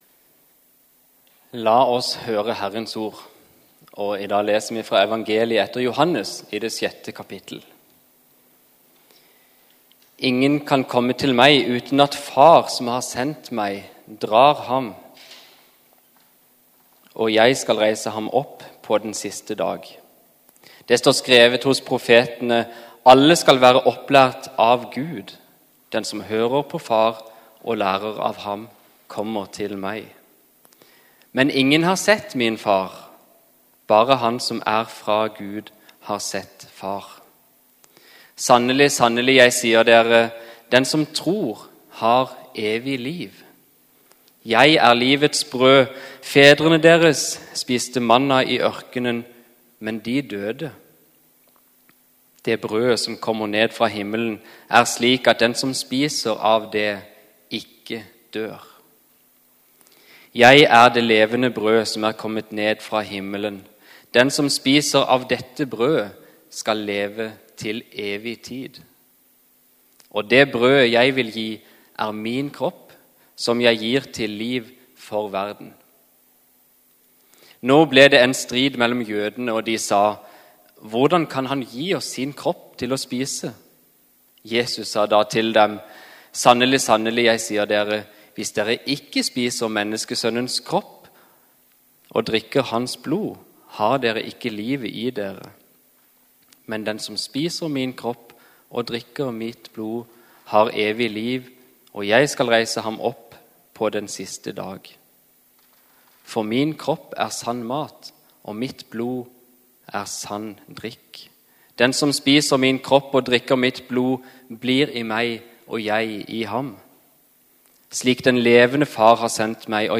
Gudstjeneste 22. august 2021,- Hele Oslo invitert til bords | Storsalen